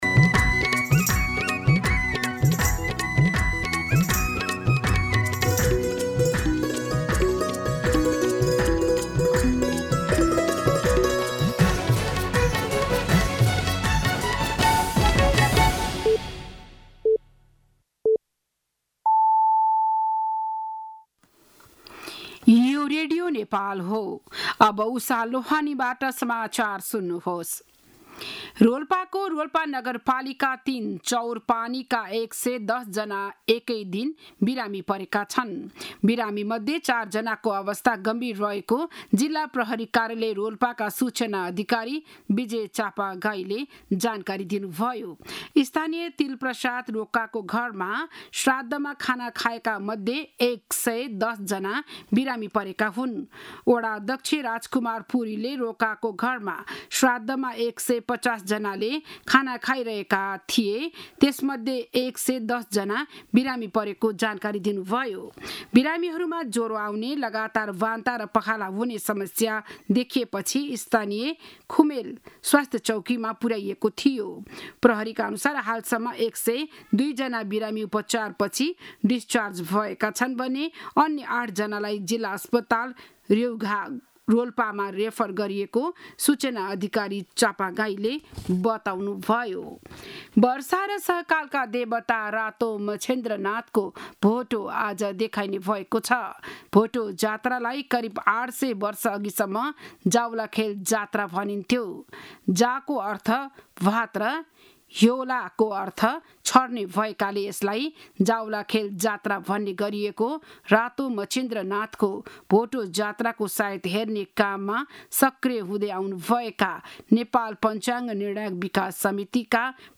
बिहान ११ बजेको नेपाली समाचार : १८ जेठ , २०८२